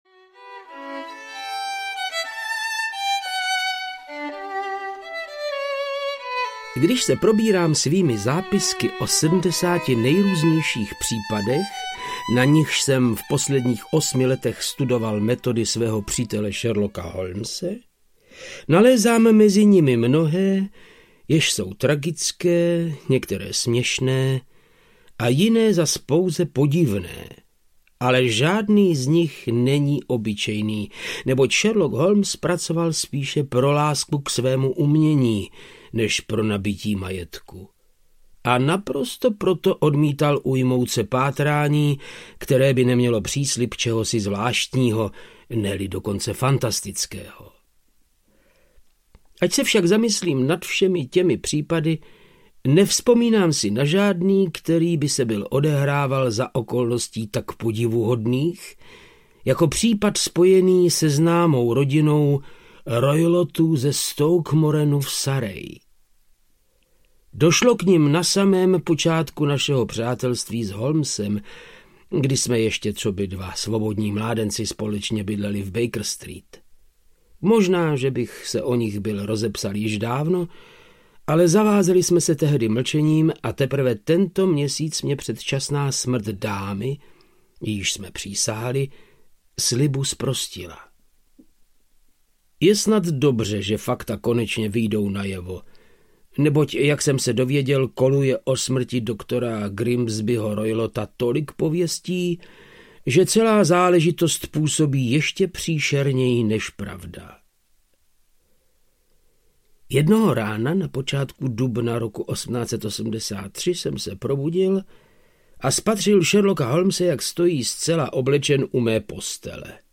Strakatý pás audiokniha
Ukázka z knihy
• InterpretVáclav Knop